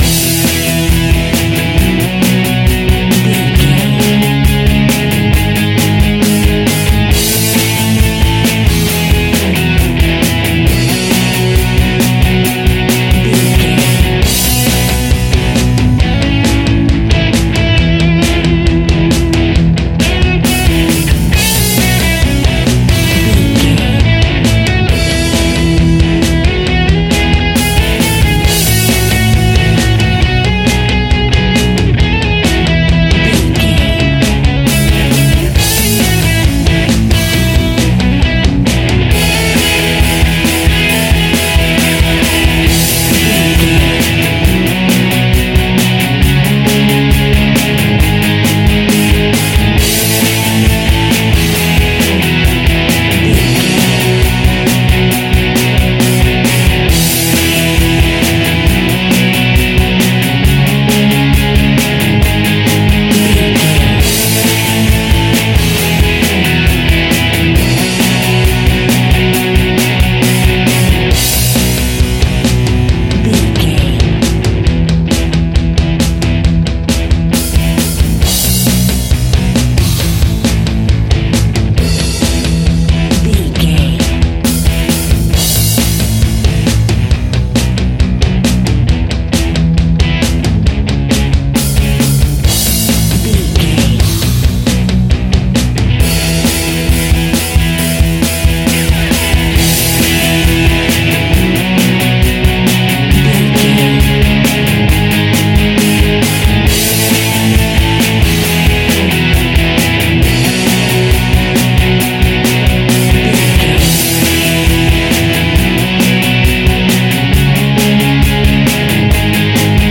Ionian/Major
melancholic
happy
energetic
smooth
uplifting
electric guitar
bass guitar
drums
Pop Punk
indie rock